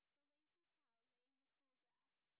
sp17_white_snr10.wav